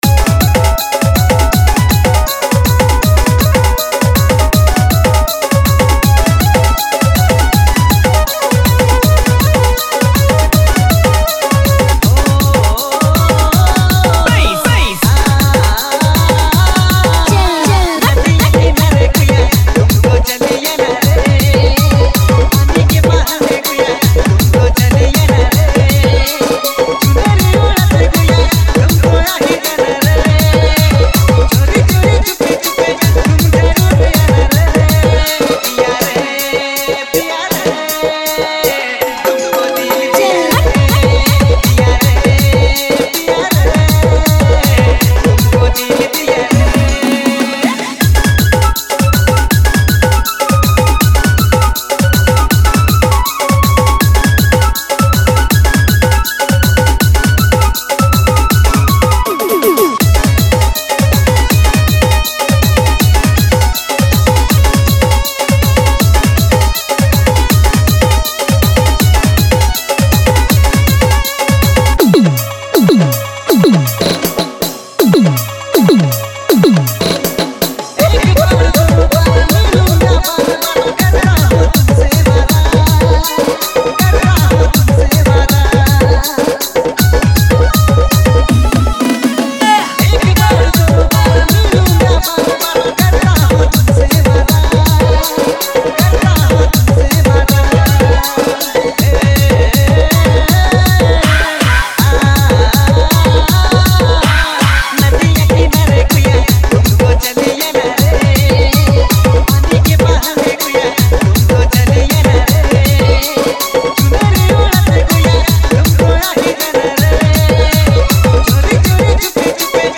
nagpuri DJ song